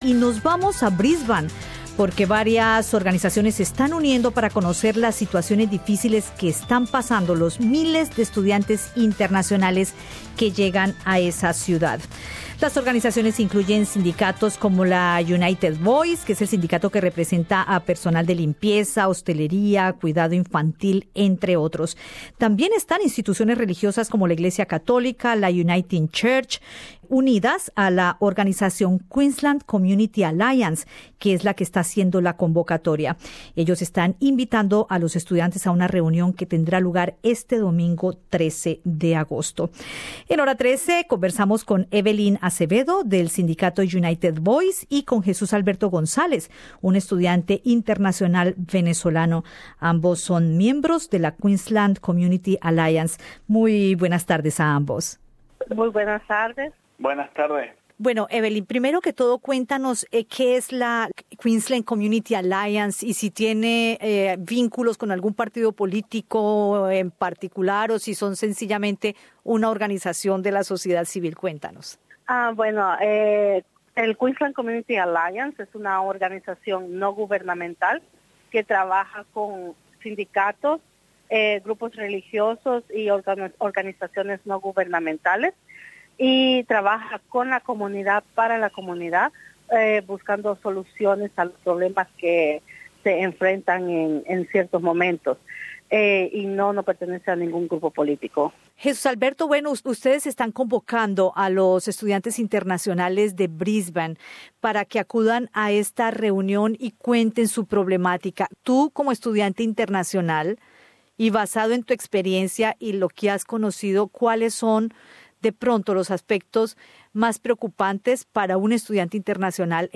Sobre la invitación a esta reunión y las expectativas de quienes asistan, conversamos en Radio SBS